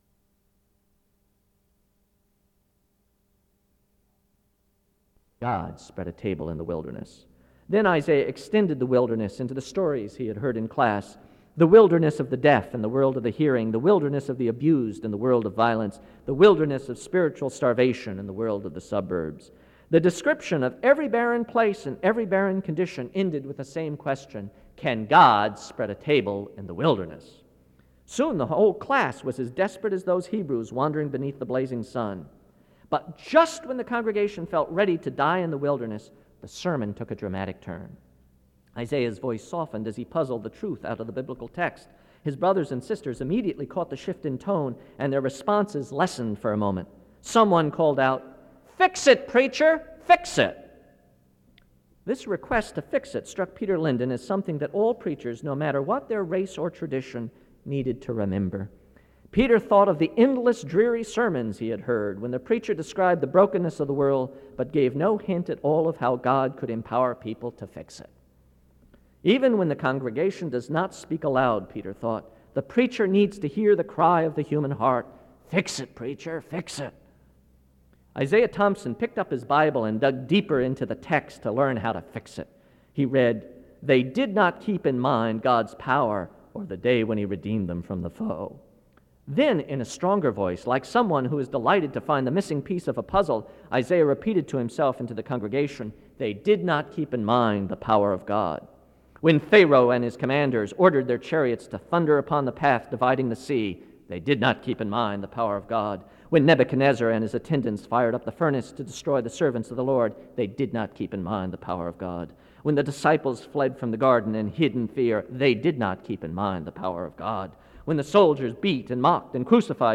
The service starts with prayer from 0:00-1:26.
SEBTS Chapel and Special Event Recordings